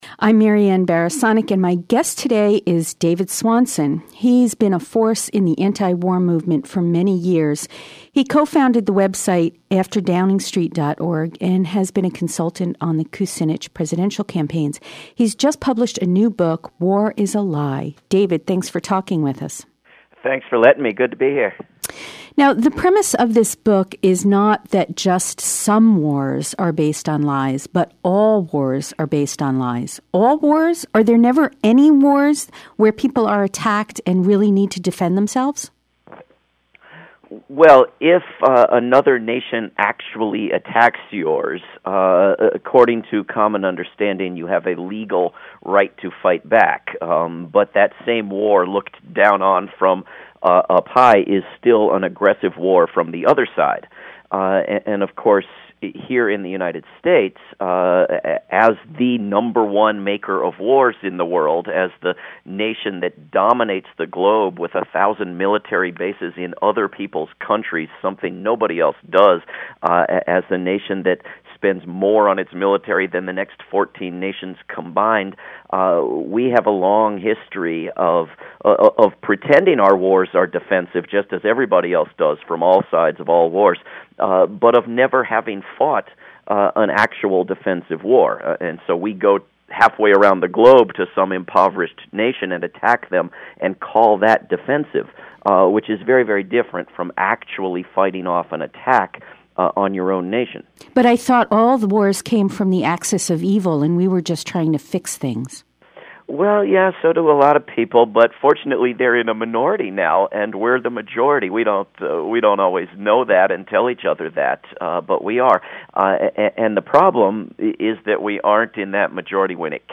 He addresses the web of lies, the taboo subjects, the false claims, and the mythic messages and lays waste to them. Your phone calls are welcome.